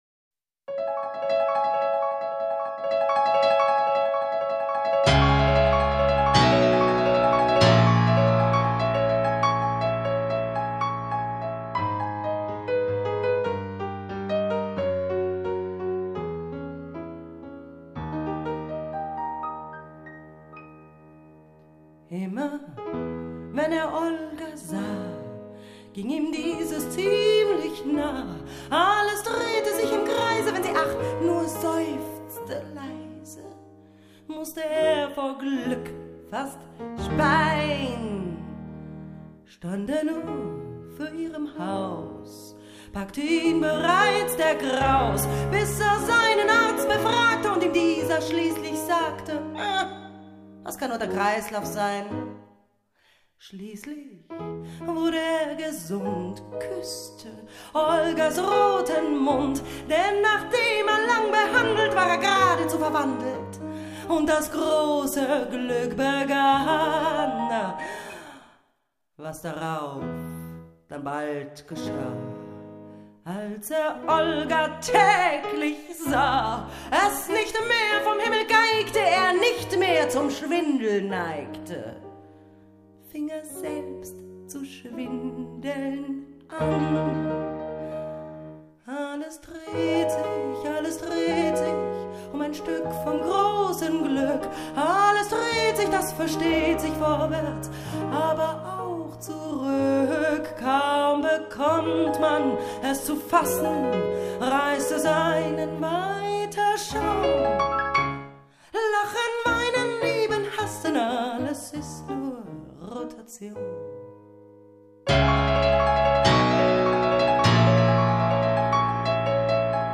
Chansons
Chansonsduo